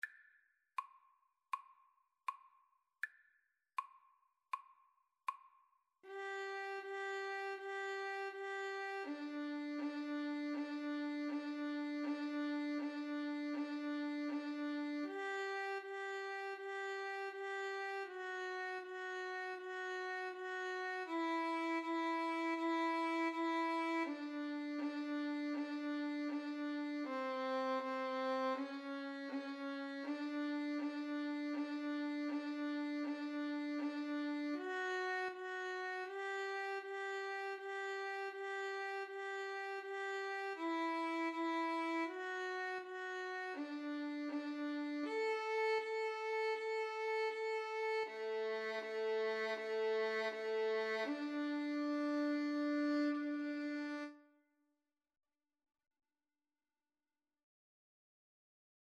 Largo =80
Violin Duet  (View more Easy Violin Duet Music)